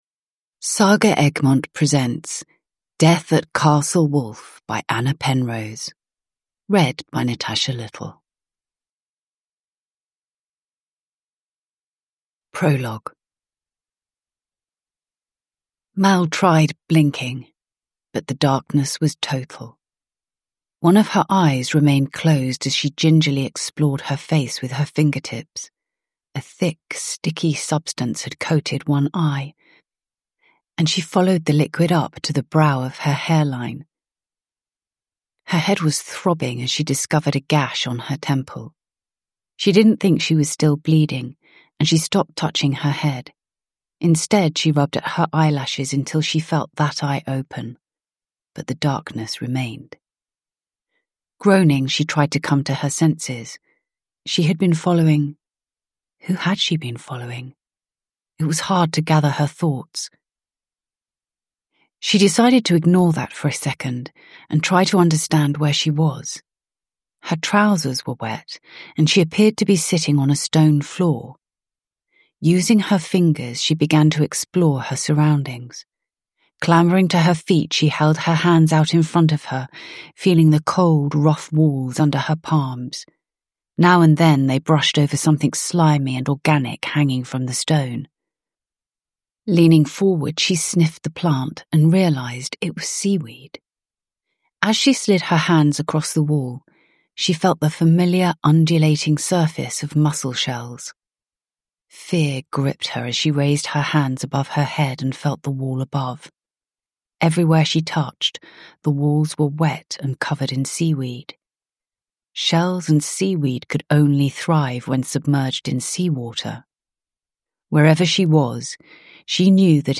Uppläsare: Natasha Little